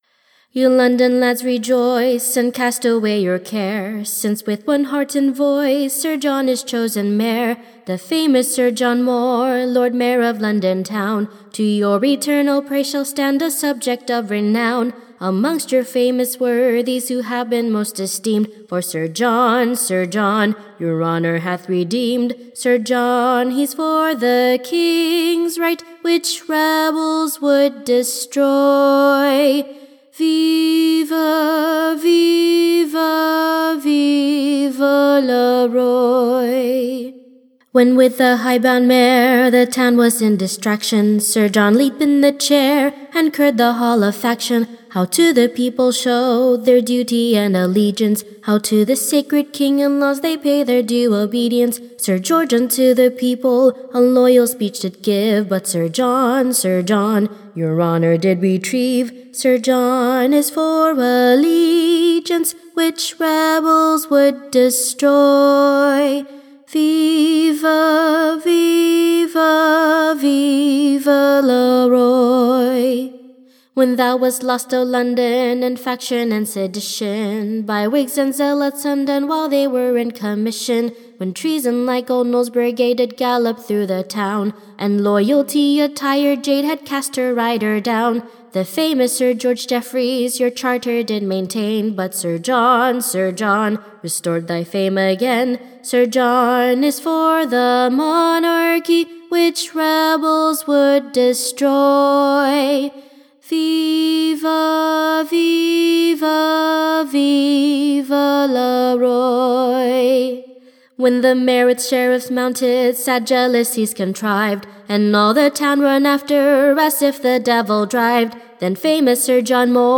EBBA 35063 - UCSB English Broadside Ballad Archive